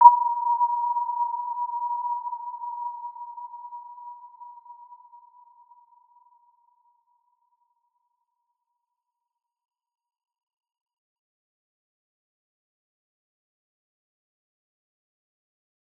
Warm-Bounce-B5-mf.wav